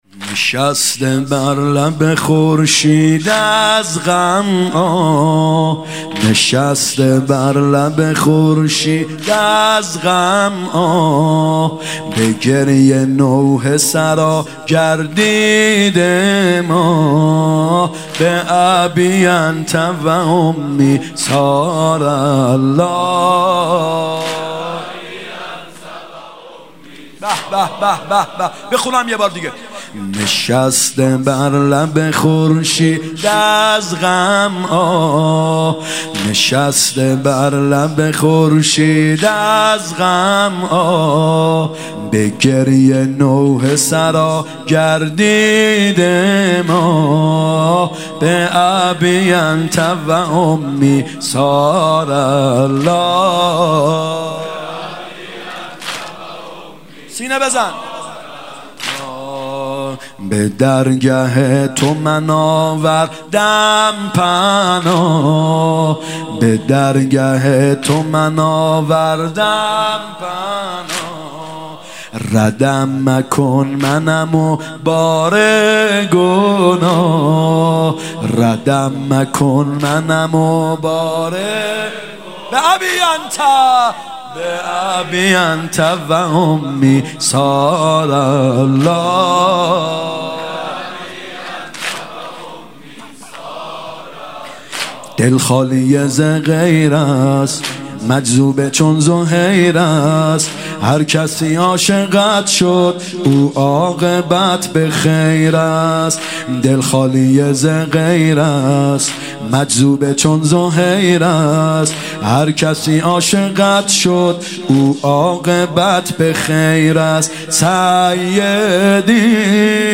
محرم 96 شب یک واحد
محرم 96(هیات یا مهدی عج)